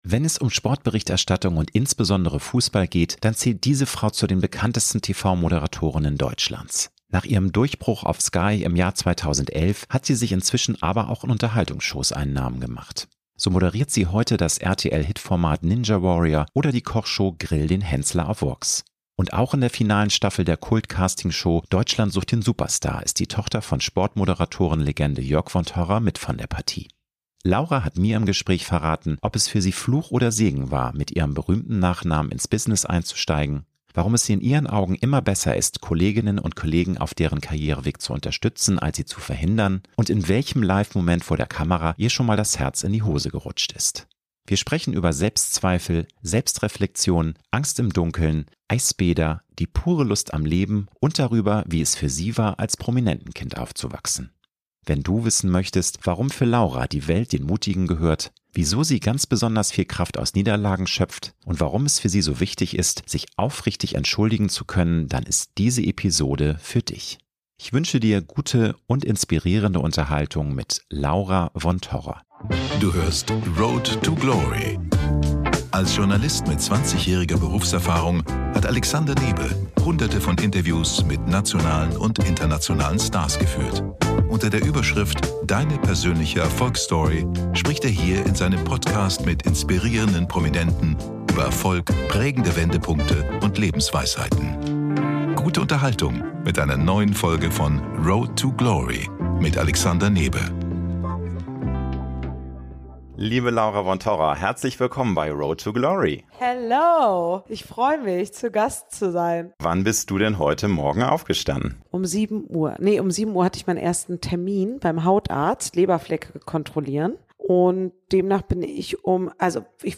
Wenn es um Sportberichterstattung und insbesondere Fußball geht, dann zählt diese Frau zu den bekanntesten TV-Moderatorinnen Deutschlands.